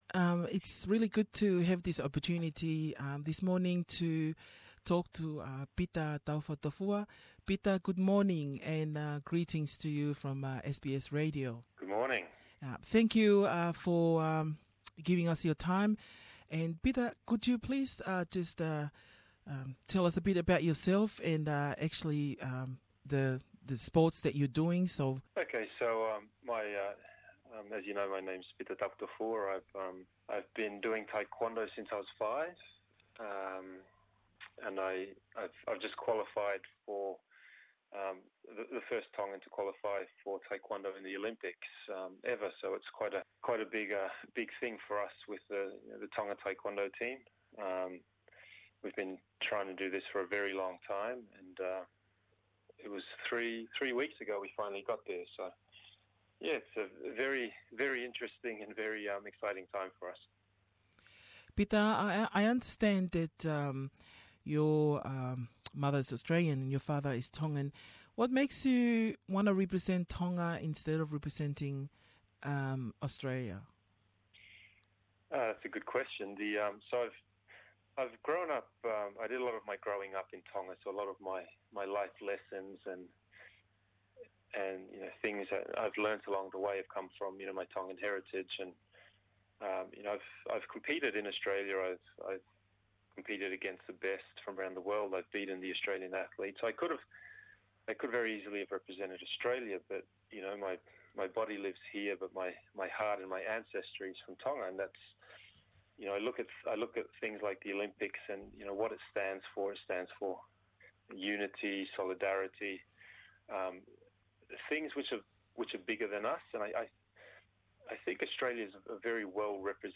Interview with Pita Taufatofua